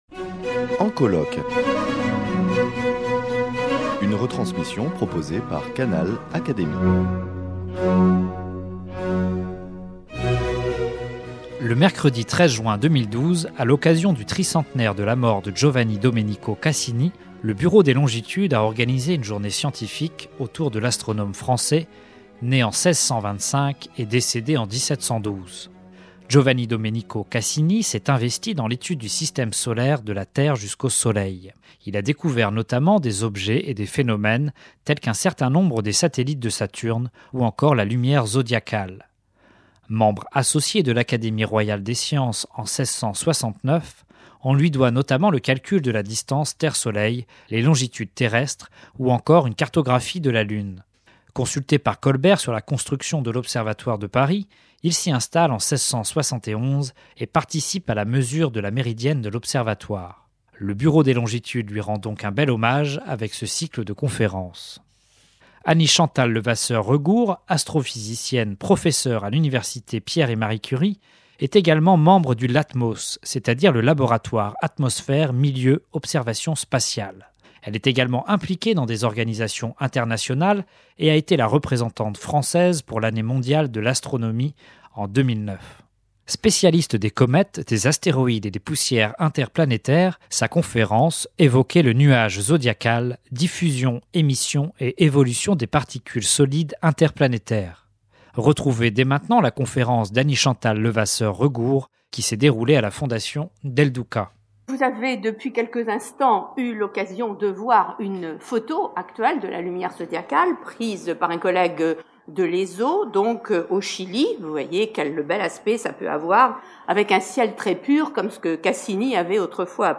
A l’occasion du tricentenaire de la mort de Cassini, le Bureau des Longitudes a organisé , une journée scientifique autour de l’astronome français né en 1625 et décédé en 1712, académicien des sciences.